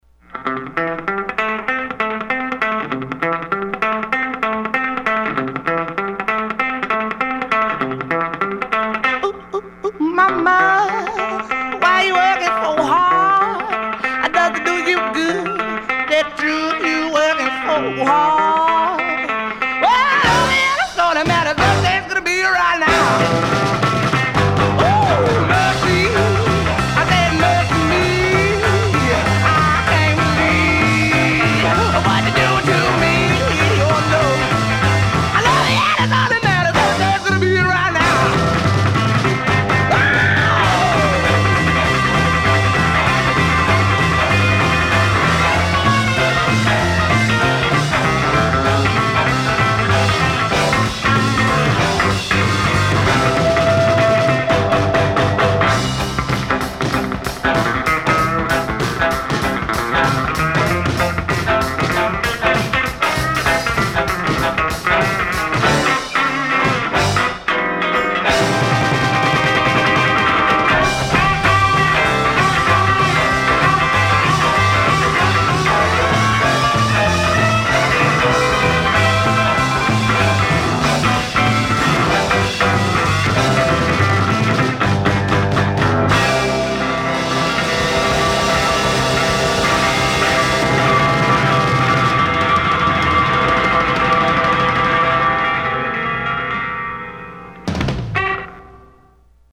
I love the guitar tones off the top of this song.